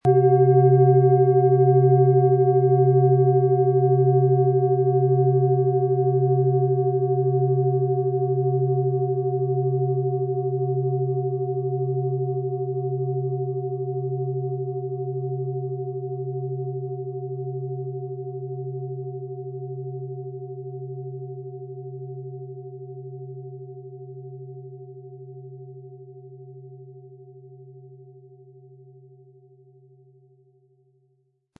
Wie klingt diese tibetische Klangschale mit dem Planetenton Tageston?
Im Sound-Player - Jetzt reinhören können Sie den Original-Ton genau dieser Schale anhören.
MaterialBronze